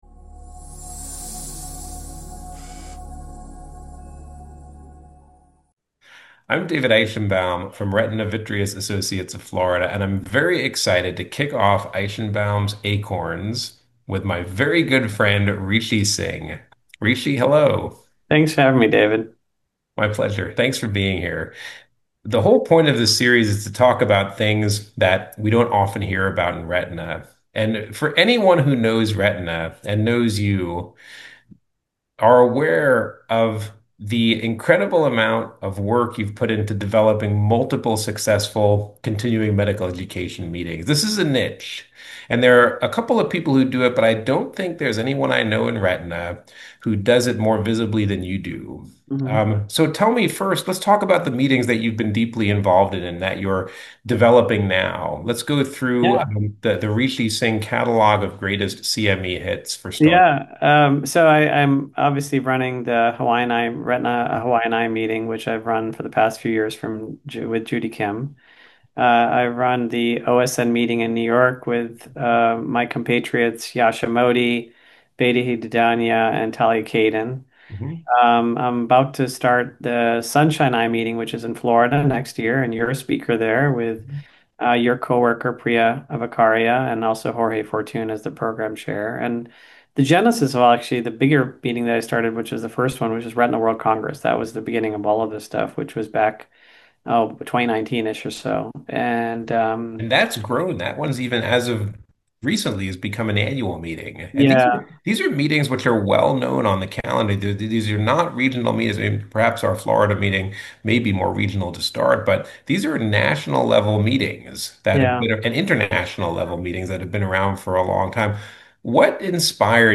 A leadership conversation